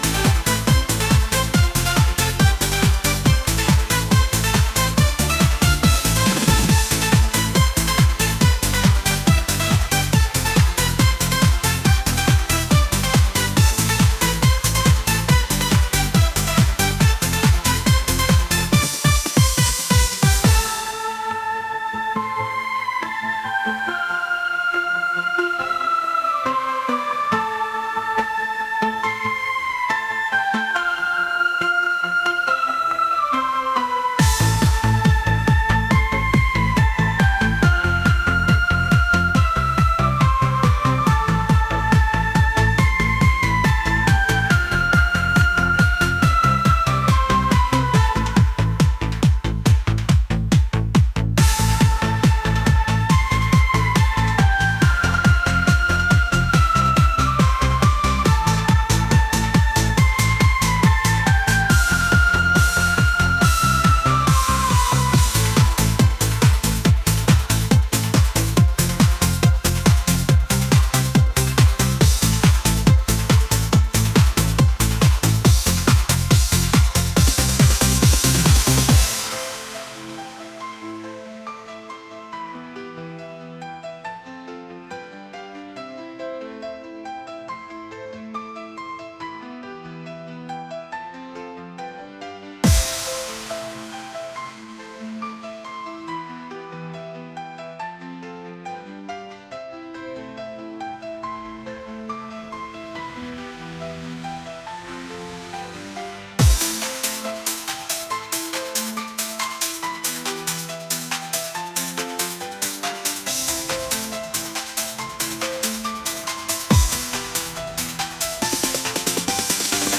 pop | electronic | energetic